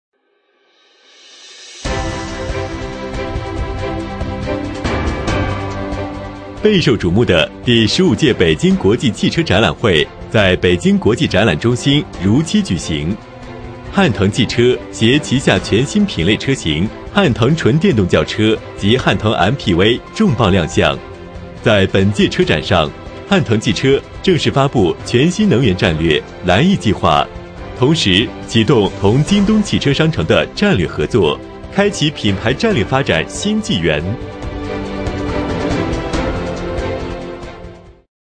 【男31号新闻播报】第十五届北京汽车展览会
【男31号新闻播报】第十五届北京汽车展览会.mp3